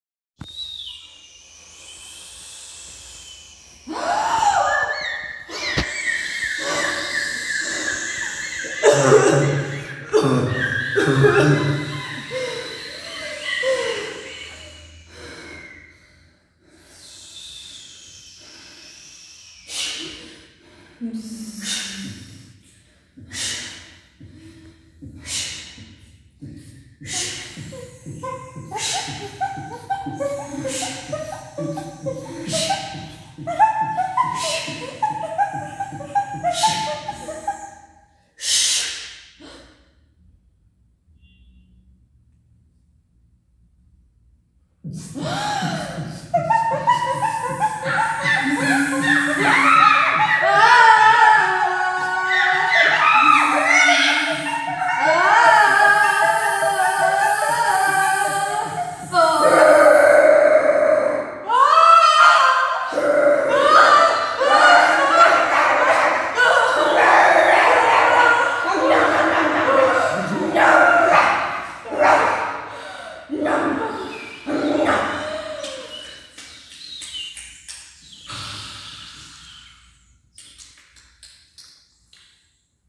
Zabawa z dźwiękami
Grupa zaawansowana oraz młodzieżowa Teatru Szóstka wykonały ćwiczenia polegające na odtworzeniu danego tematu jedynie za pomocą wydawanych przez siebie dźwięków. Dodatkowym utrudnieniem było to że można było używać wyłącznie dźwięków wydawanych własnym głosem.